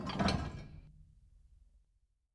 heavy-sliding-lock.ogg